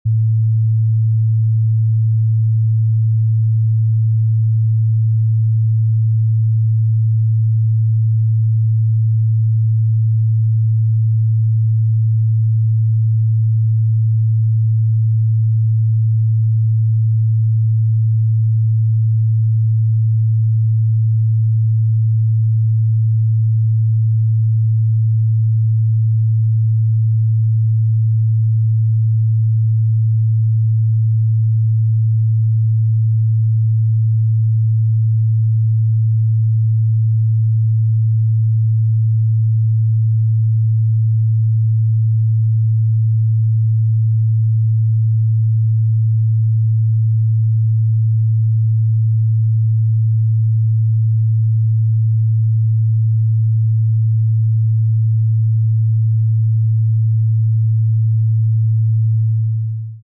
111 HZ CELLULAR REGENERATION FREQUENCY sound effects free download
111 Hz is a frequency known for its spiritual, calming, and healing properties.